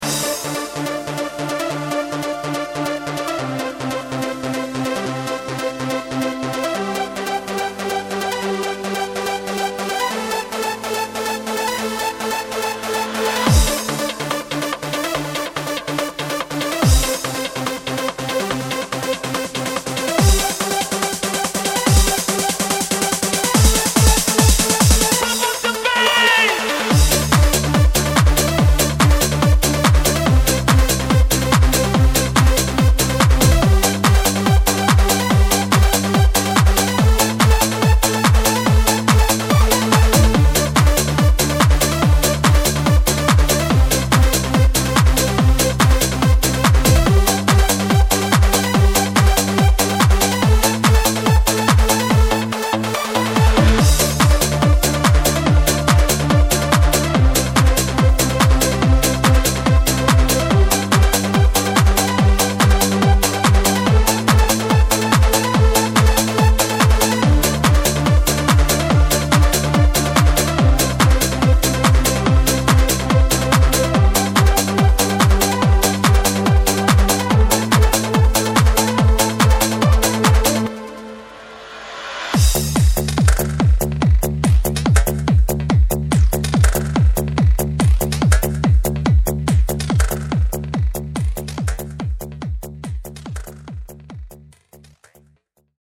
Hard-Trance, Trance